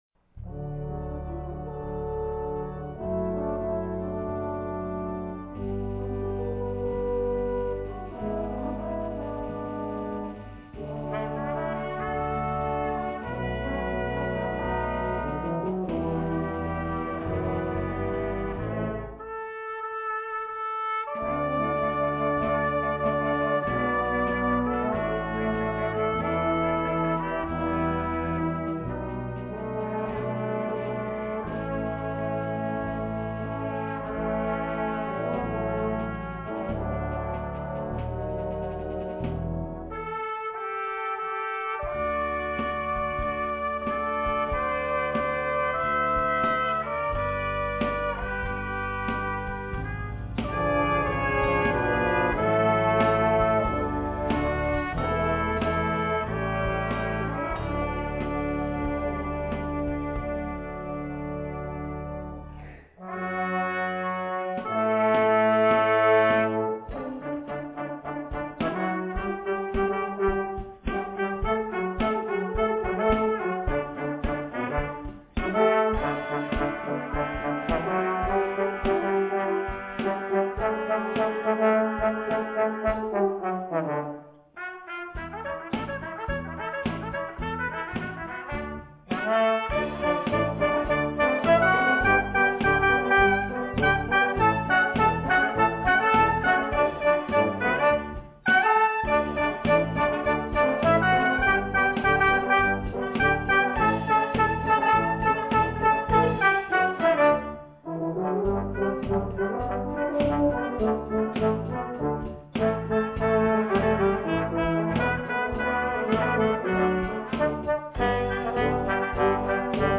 Sunday PM Music - March 20, 2011
Brass Ensemble